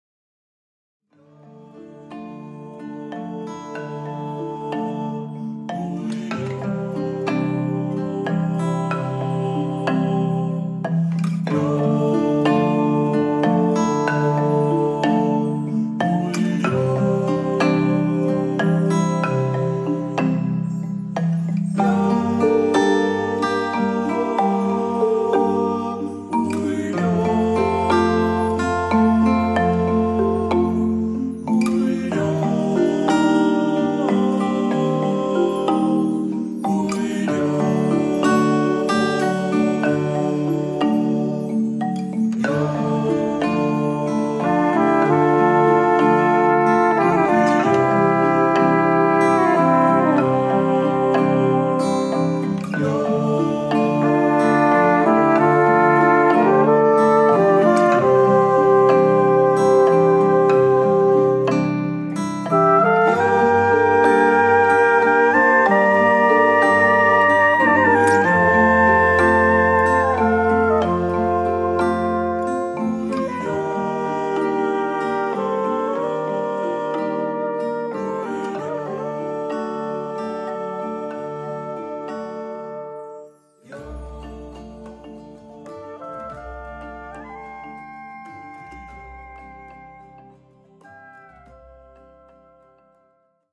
Musikintro